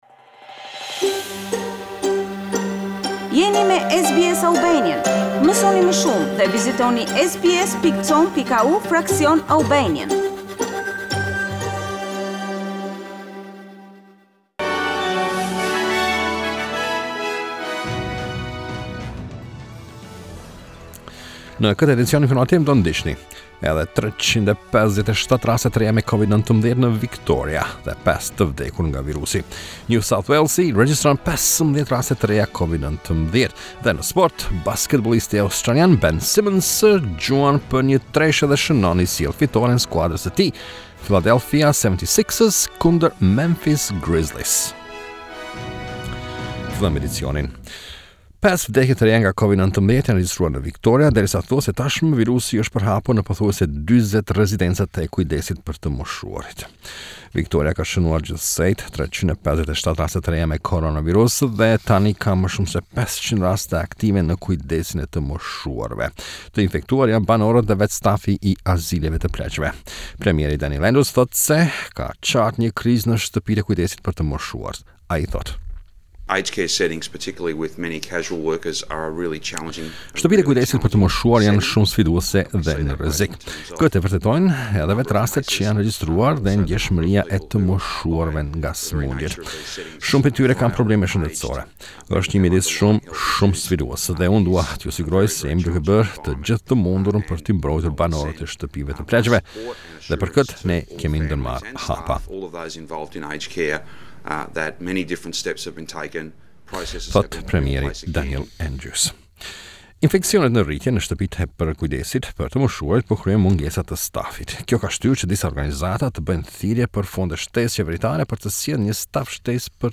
SBS News Bulletin - 25 July 2020